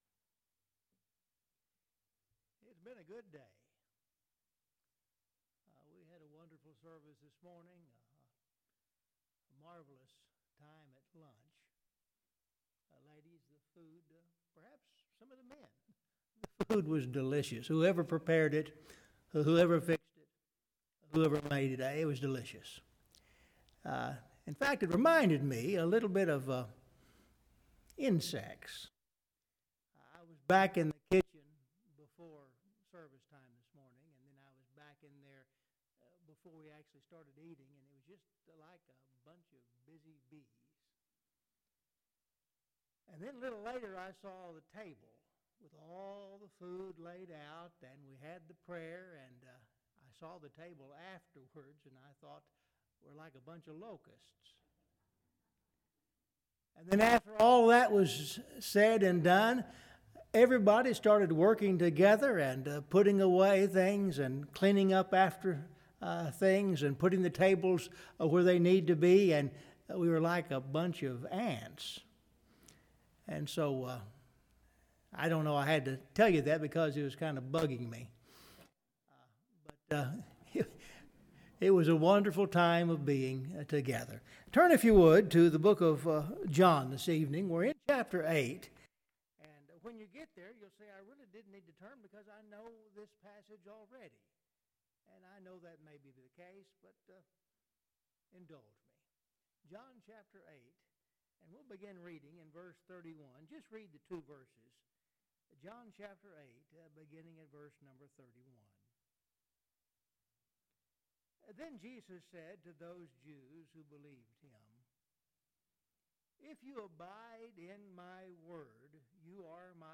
John 8:31,32 (The audio is weak until the 2:36 mark)